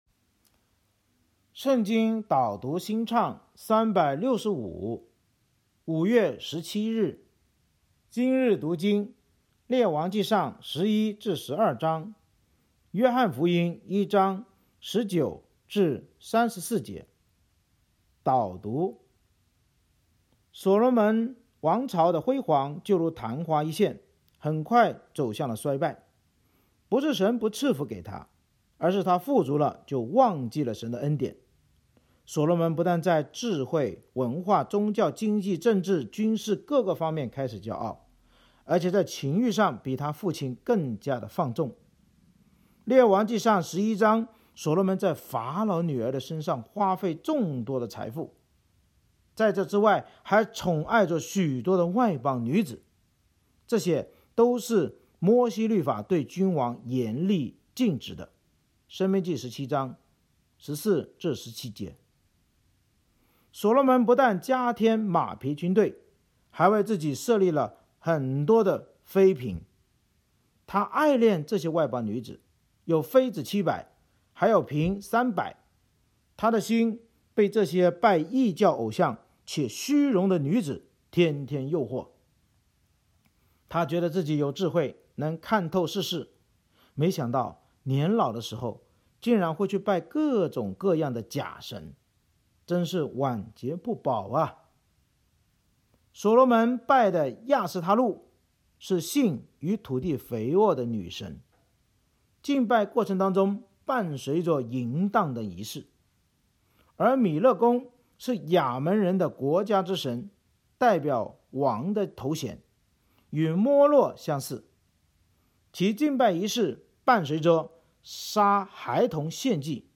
圣经导读&经文朗读 – 05月17日（音频+文字+新歌）